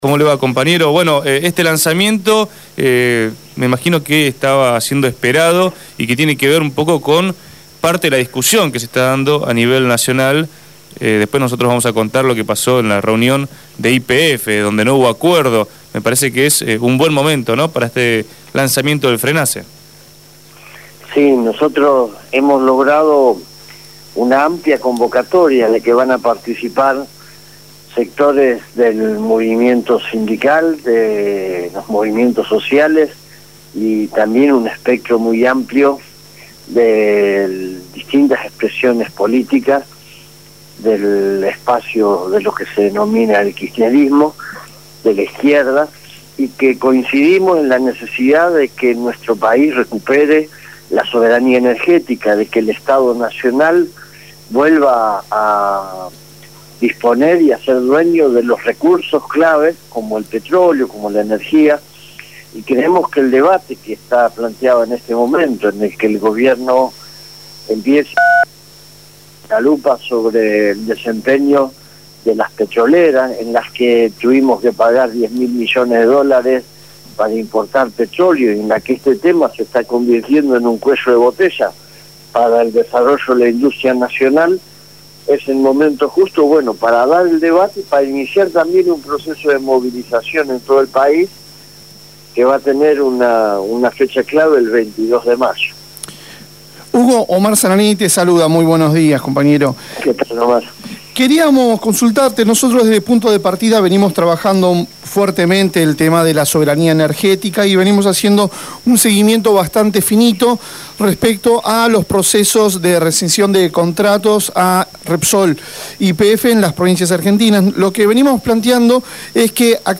Hugo Yasky, secretario general de la Central de Trabajadores de la Argentina (CTA), habló en Punto de Partida.